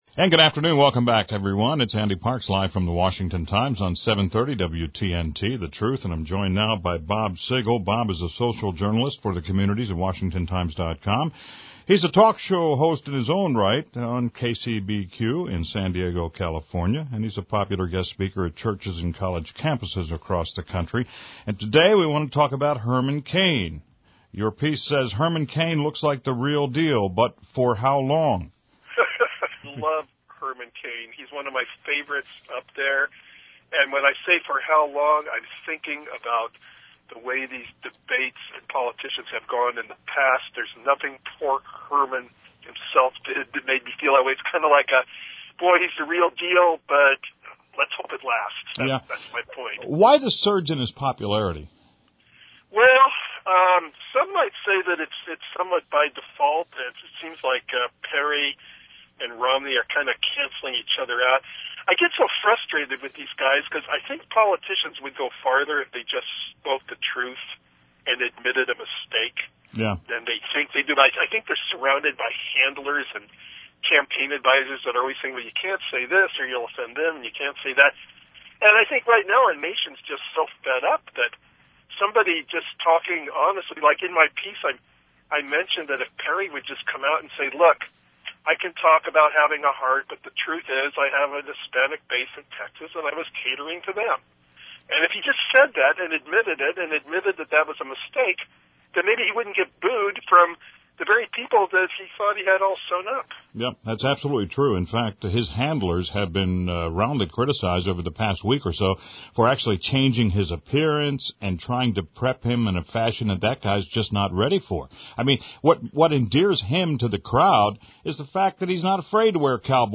This short broadcast  is actually an interview